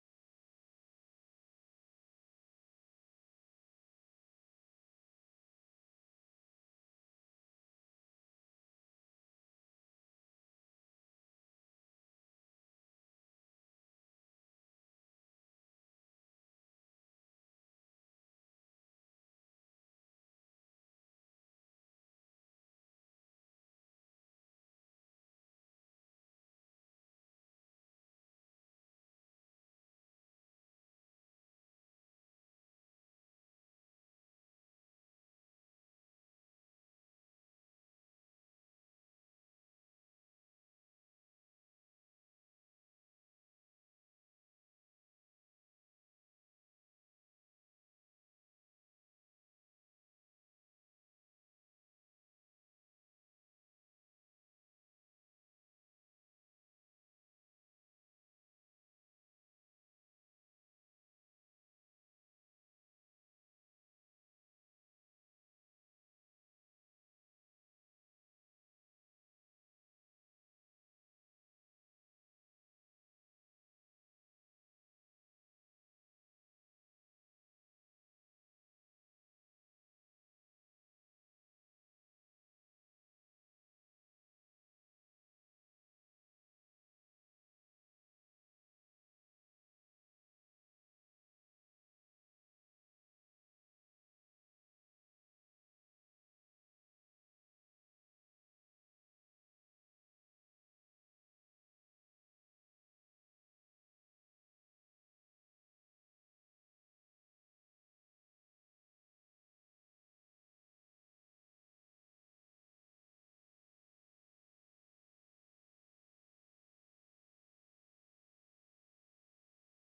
November 14, 2021 (Morning Worship)